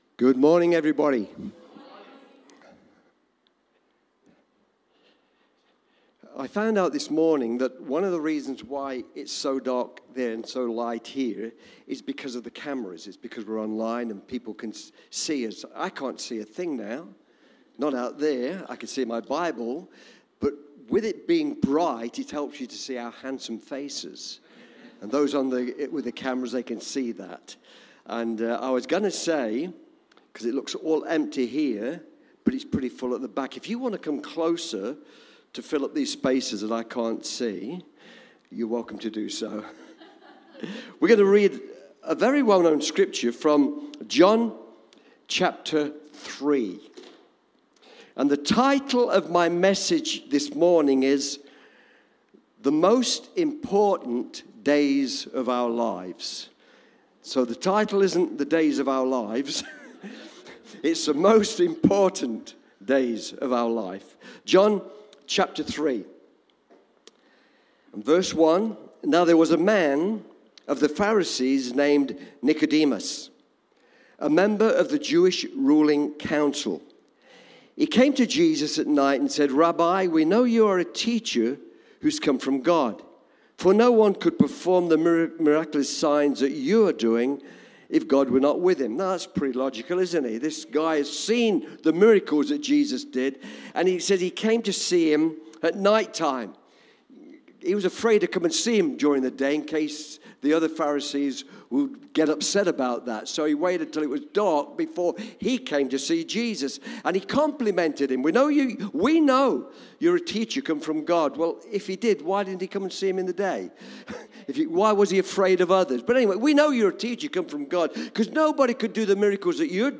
Cityview-Church-Sunday-Service-Special-Days-of-our-Lives.mp3